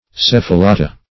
Search Result for " cephalata" : The Collaborative International Dictionary of English v.0.48: Cephalata \Ceph`a*la"ta\, n. pl.
cephalata.mp3